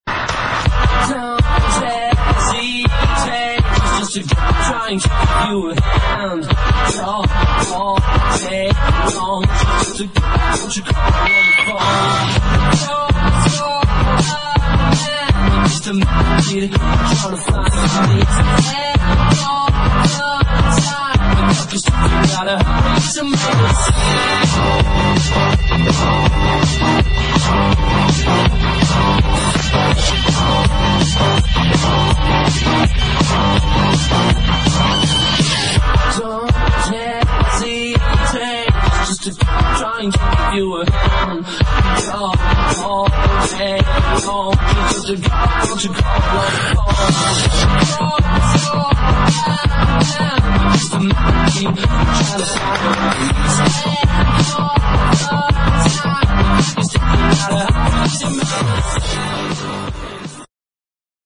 INDIE DANCE# 00’s ROCK# ELECTRO